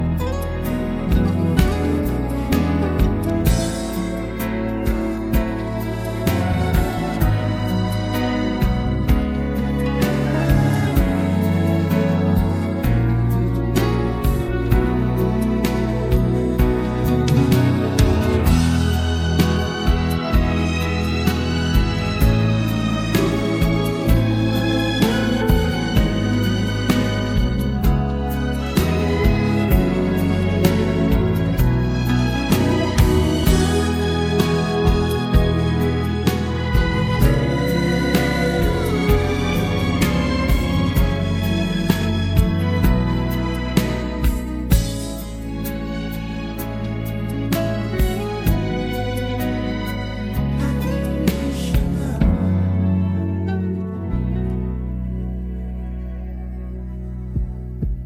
음정 -1키 4:04
장르 가요 구분 Voice MR